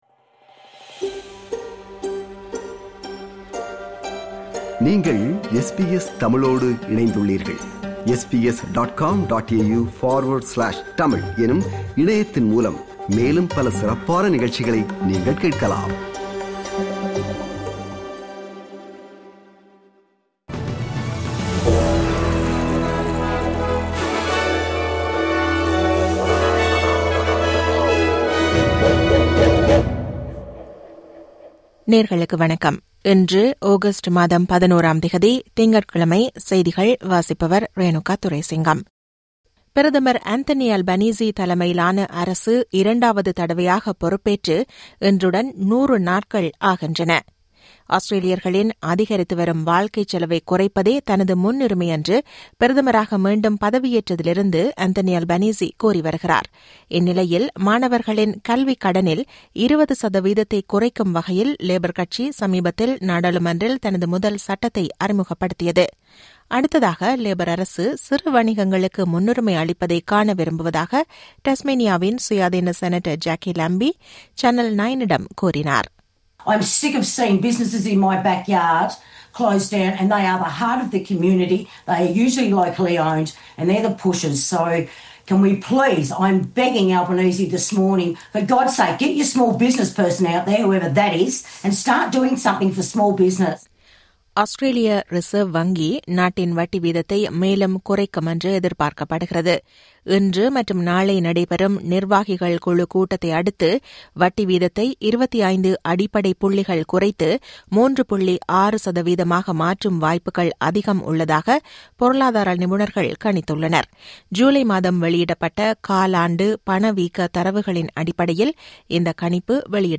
SBS தமிழ் ஒலிபரப்பின் இன்றைய (திங்கட்கிழமை 11/08/2025) செய்திகள்.